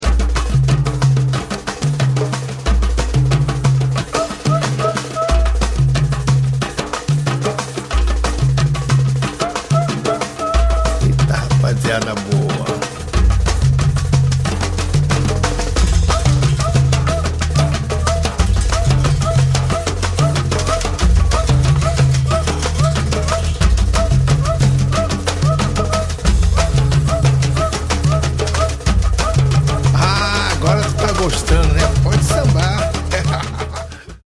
Cavaquinho,Violão, Viola Caipira e Violão Tenor
acordeom
Trompete e Flugelhorn
Sax Tenor e Alto